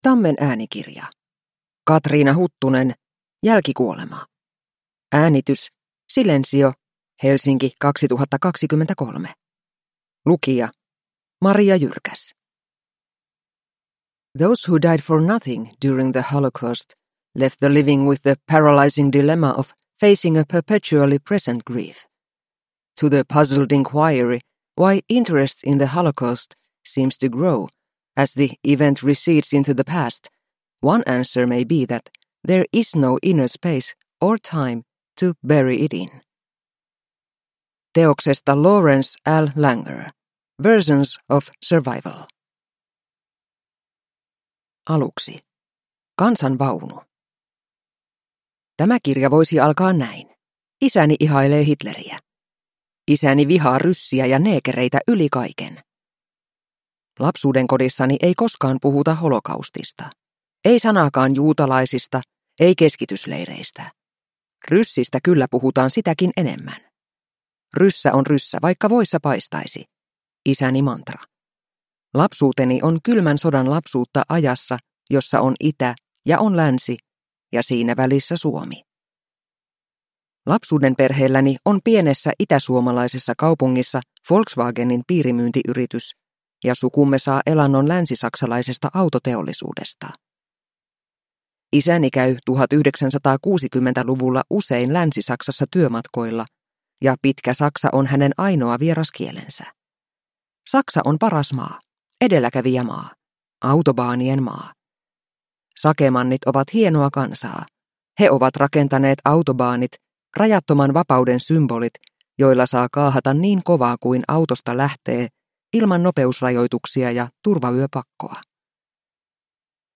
Jälkikuolema – Ljudbok – Laddas ner